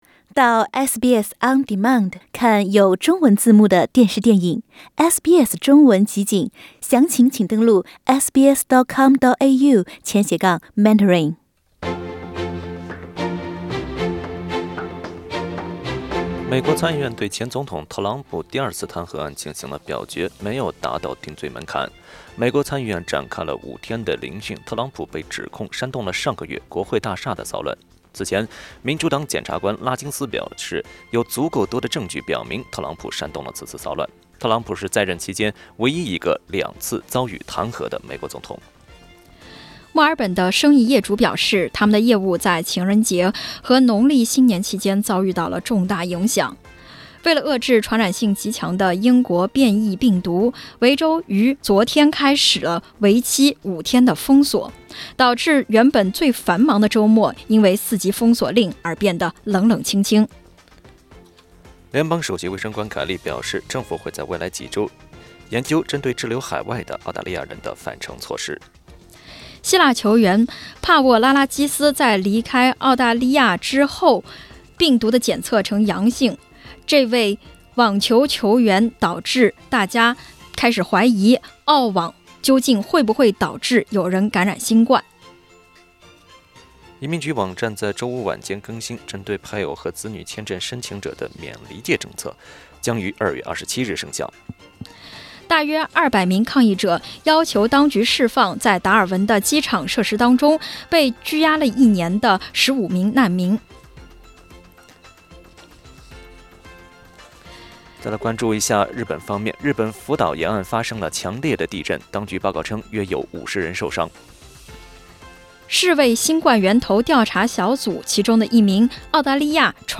SBS早新聞（2月14日）